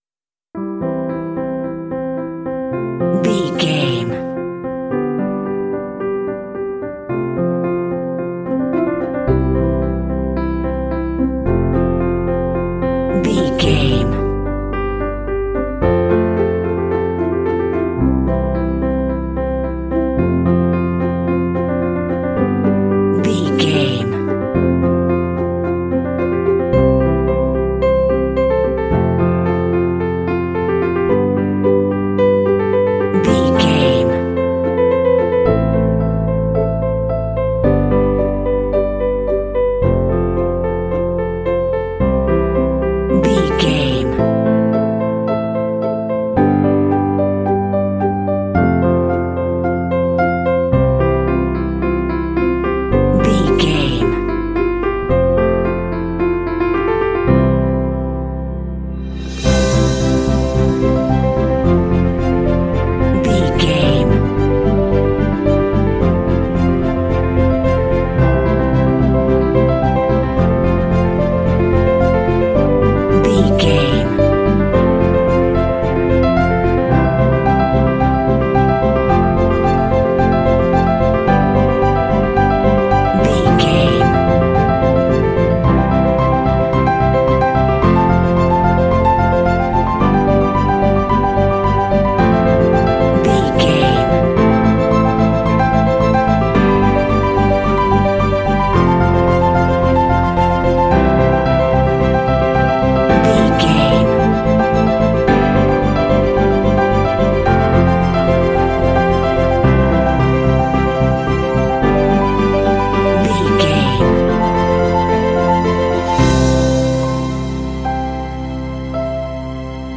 Ionian/Major
optimistic
happy
bright
piano
strings
contemporary underscore